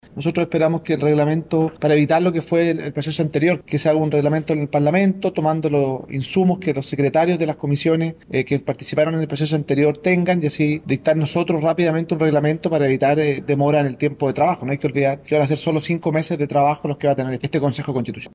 Sin embargo, el diputado UDI, Juan Antonio Coloma, señaló que también están discutiendo que sea el Congreso Nacional, y no el órgano mismo, el que defina el reglamento del nuevo Consejo Constitucional.